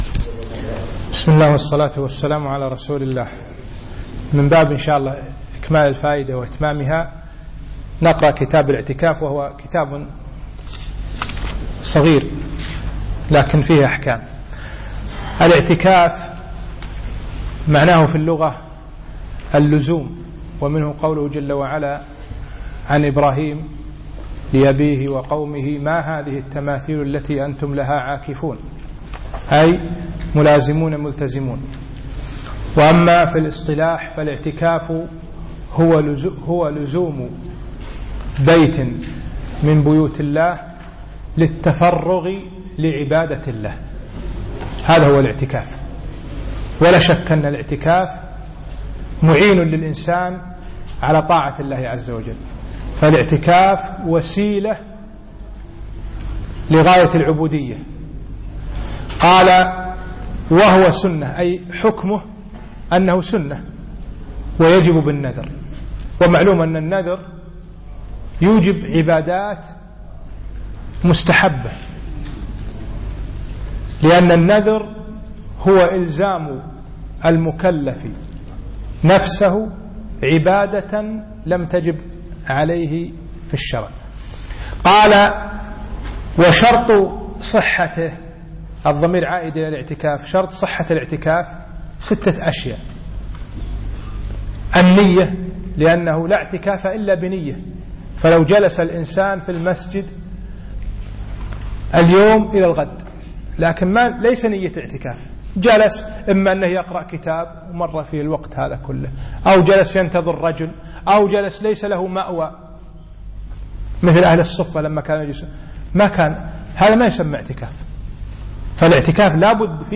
يوم الثلاثاء 15 شعبان 1436 الموافق 2 6 2015 بعد صلاة العشاء بمسجد سعد السلطان الفنطاس